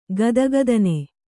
♪ gadagadane